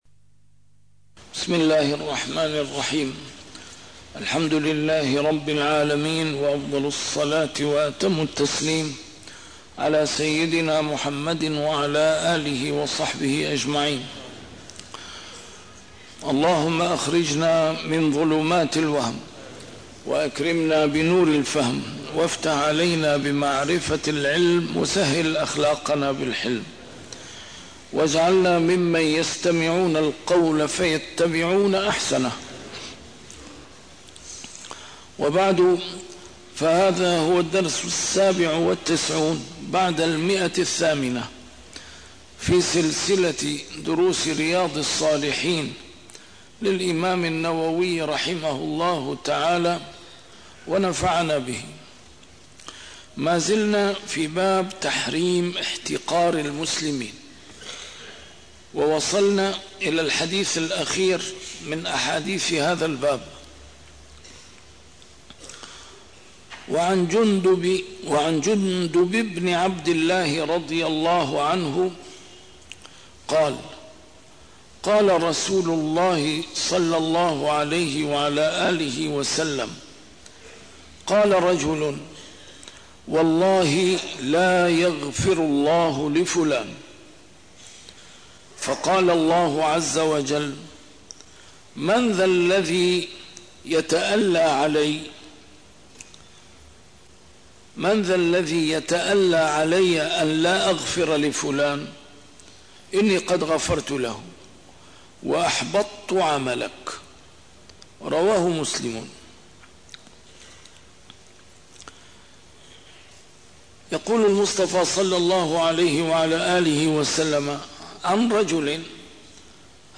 A MARTYR SCHOLAR: IMAM MUHAMMAD SAEED RAMADAN AL-BOUTI - الدروس العلمية - شرح كتاب رياض الصالحين - 897- شرح رياض الصالحين: تحريم احتقار المسلمين